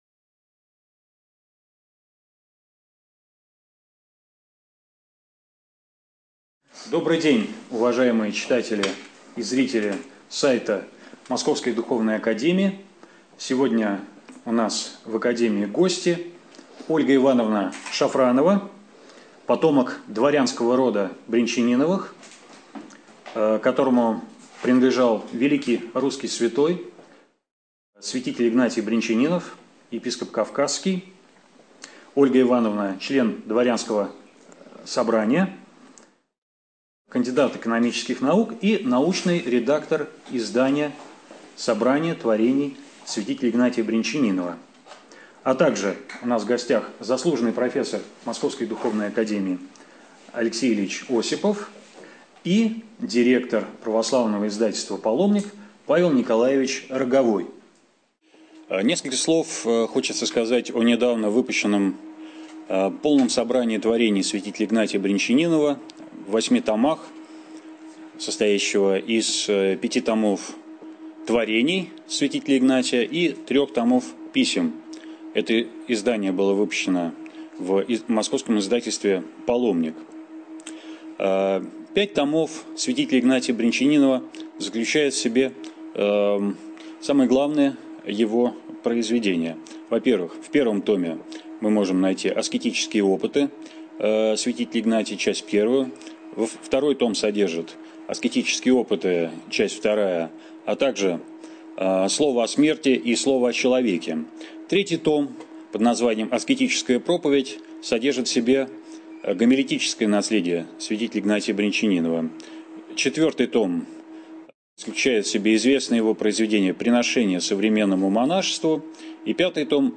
Презентация полного собрания сочинений святителя Игнатия Брянчанинова (МПДА, 2014)